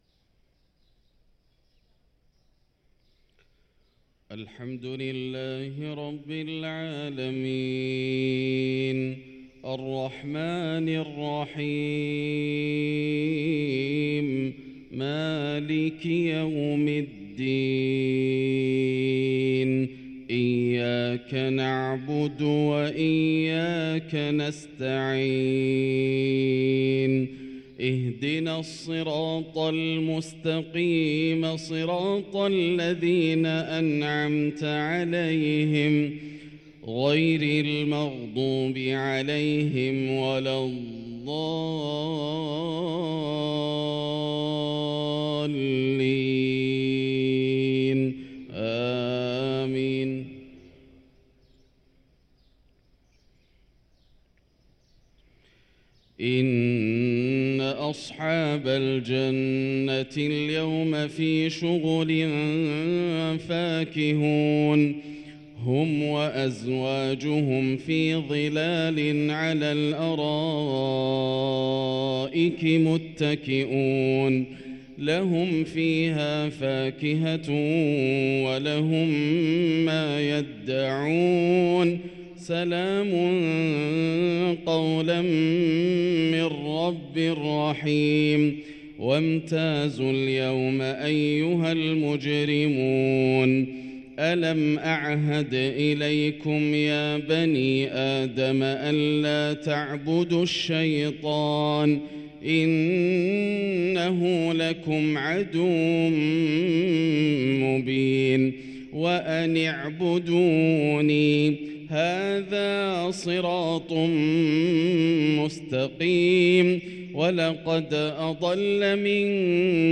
صلاة الفجر للقارئ ياسر الدوسري 12 شعبان 1444 هـ